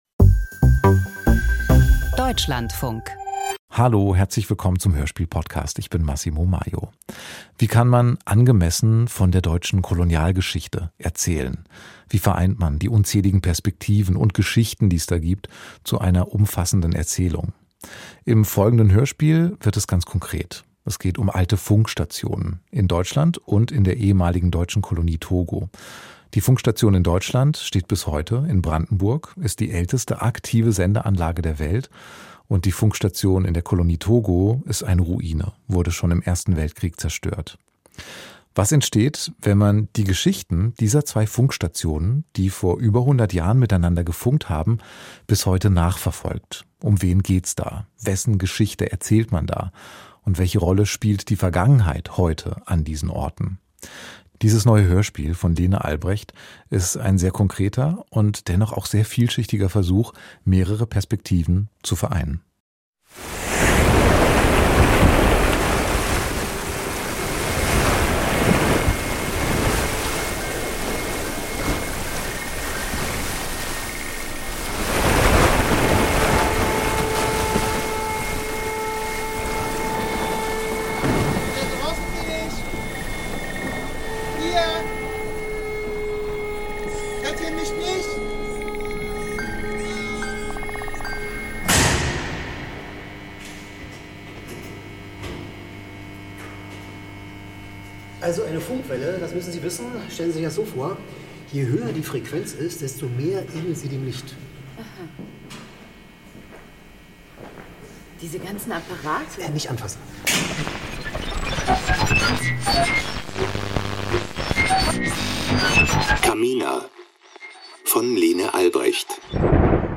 Email Audio herunterladen • Doku-Fiktion • Bevor die deutsche Kolonialmacht 1914 in Togo besiegt wird, zerstört sie ihre eigene Funkstation.